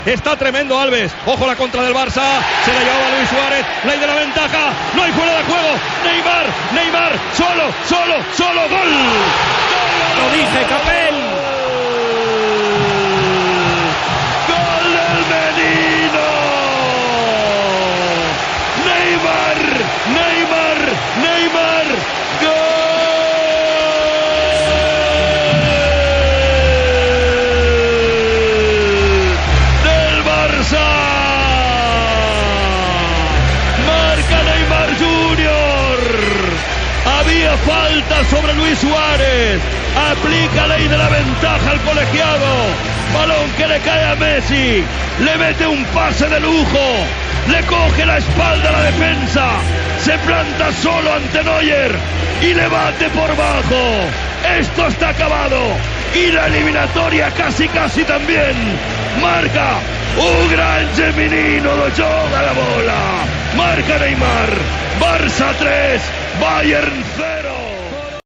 Transmissió del partit de la fase eliminatòria de la Copa d'Europa de futbol masculí entre el Futbol Club Barcelona i el Bayern München.
Final del partit amb la naracció del gol de Neymar i reconstrucció de la jugada.
Esportiu